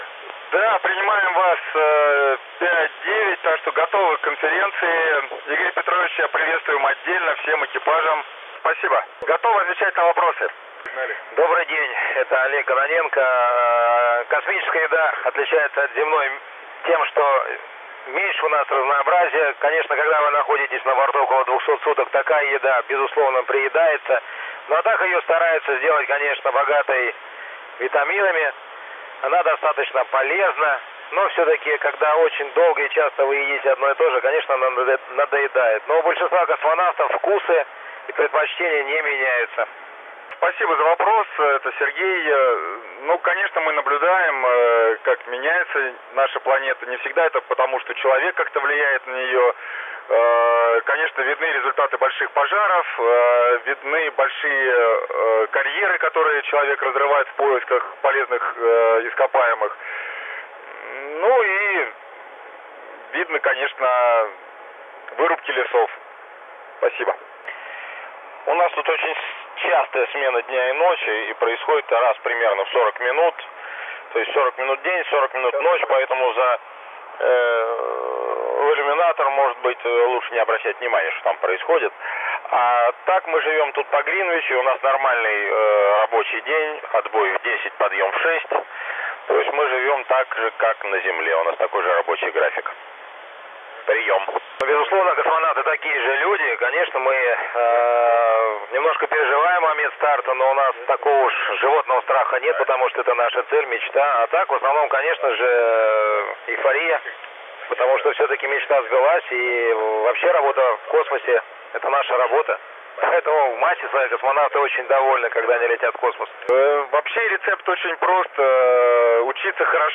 МКС на 145.800 МГц. 05.12.2015г. 19:30мск. Сеанс связи космонавтов Олега Кононенко, Михаила Корниенко и Сергея Волкова со студентами Республики Ингушетия.
Начало » Записи » Записи радиопереговоров - МКС, спутники, наземные станции
Принято в Московской области. Антенна - QFH, приемник - RTL-SDR, МШУ.